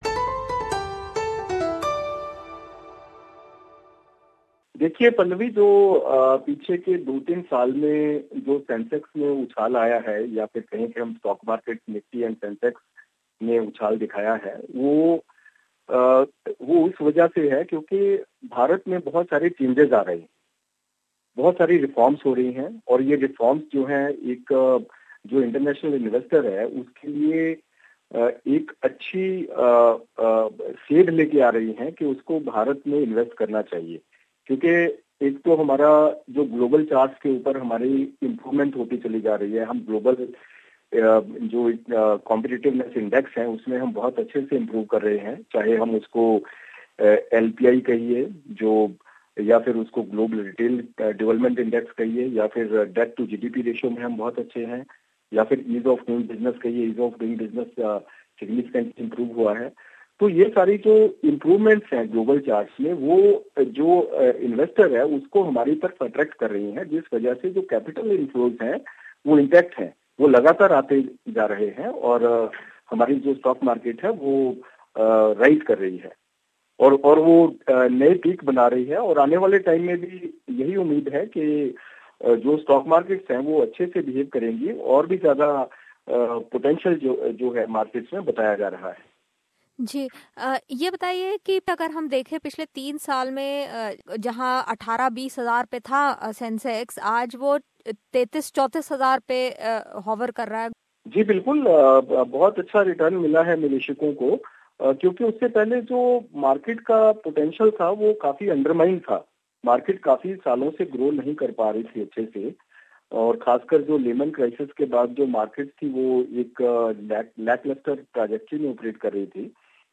(Disclaimer: This Interview is only for the purpose of News and should in no way be treated as guide for any share market.